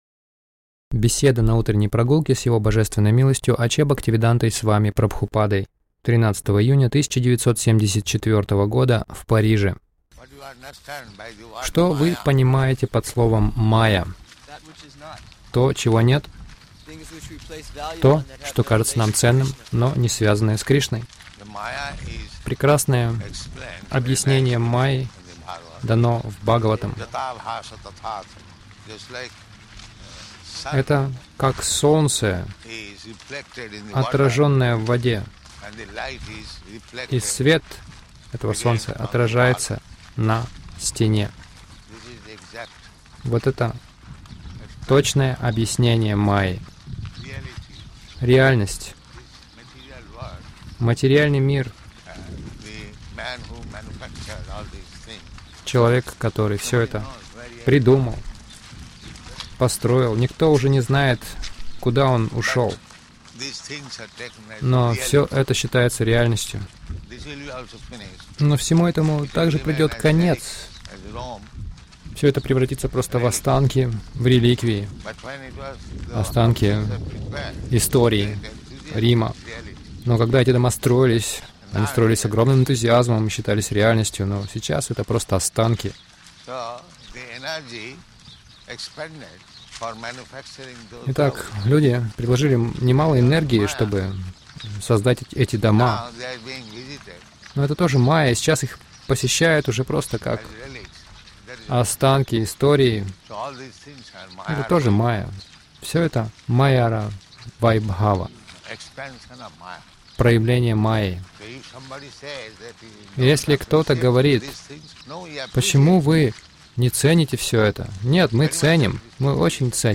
Милость Прабхупады Аудиолекции и книги 13.06.1974 Утренние Прогулки | Париж Утренние прогулки — Всему великому в истории приходит конец Загрузка...